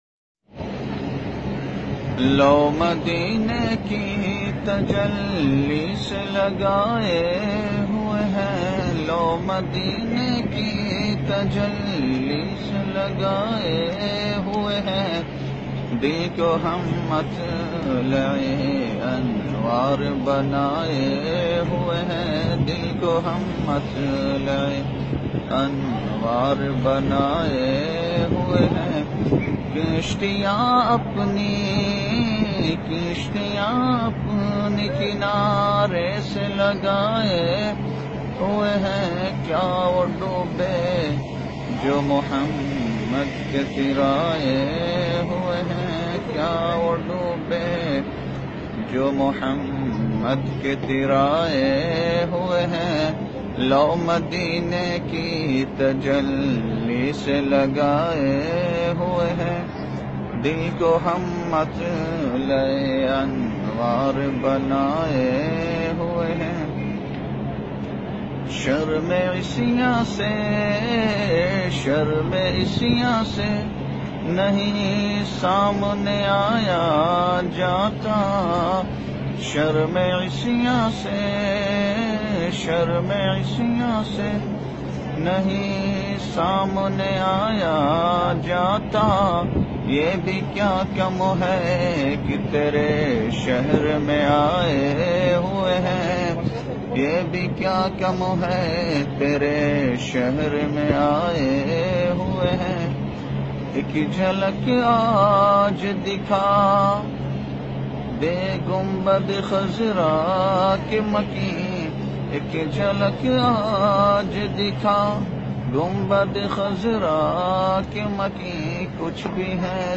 naat mp3 play online & download.